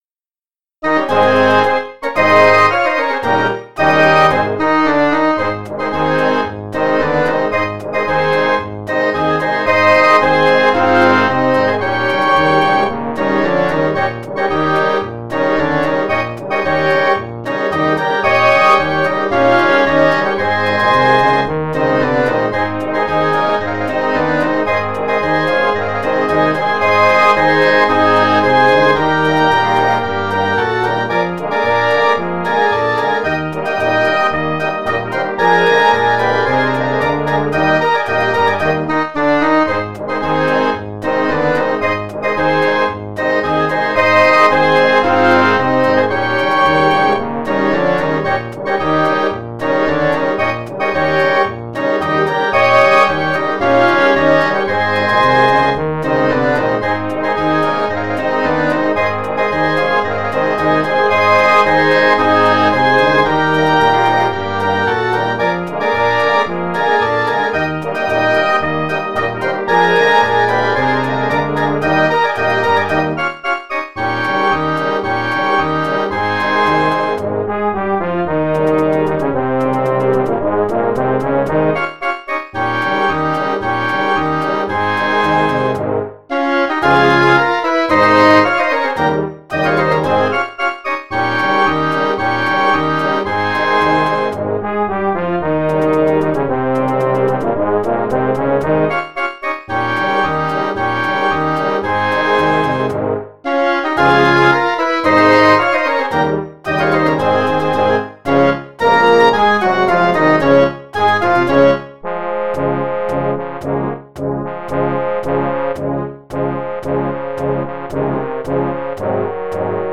Blasmusik & Orchester